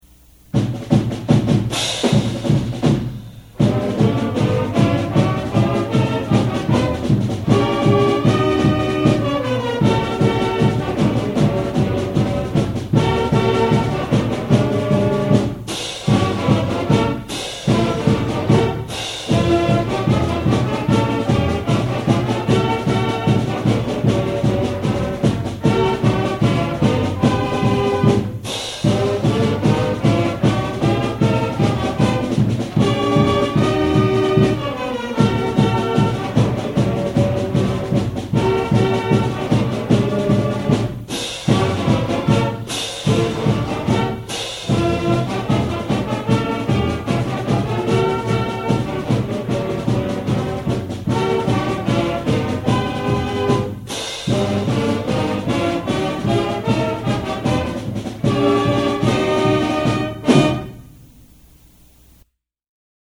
Click on the titles below to hear the Greatest Pep Band of All-Time play your favorite hits!